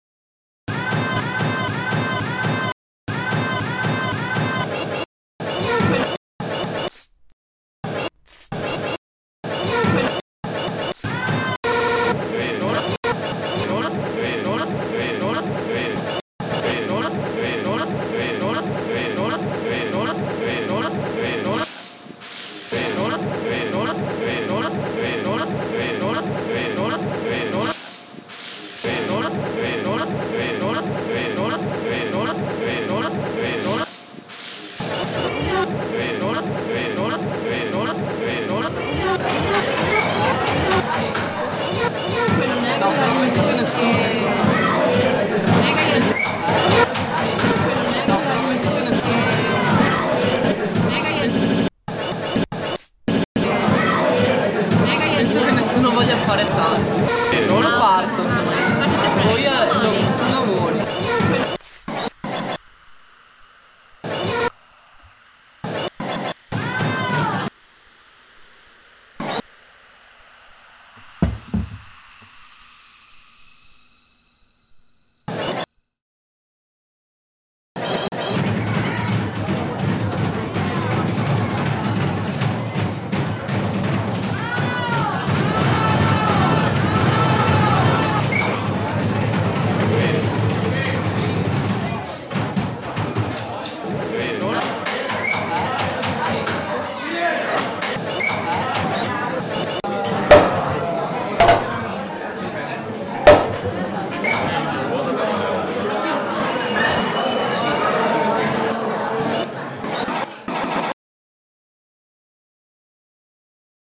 Prosecco festival at Col San Martino